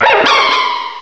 cry_not_virizion.aif